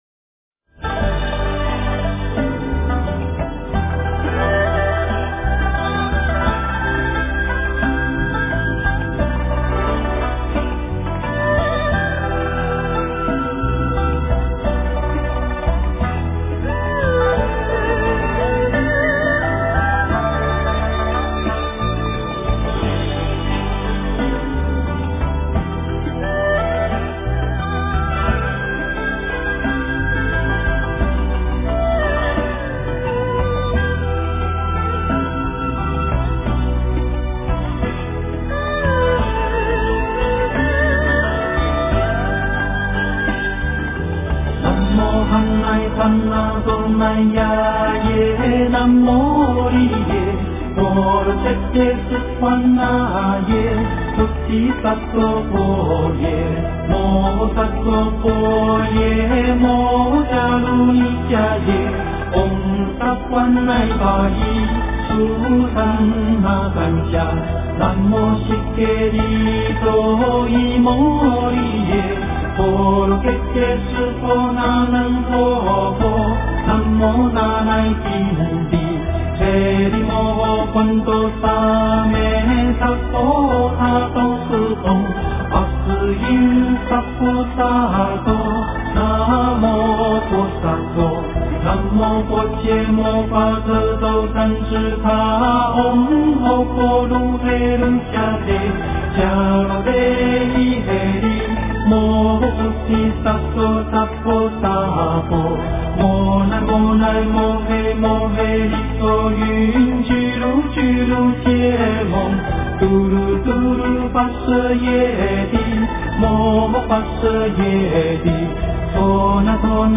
大悲咒-梵唱
大悲咒-梵唱 诵经 大悲咒-梵唱--圆满自在组 点我： 标签: 佛音 诵经 佛教音乐 返回列表 上一篇： 大悲咒 下一篇： 心经-恭诵 相关文章 In Love--July In Love--July...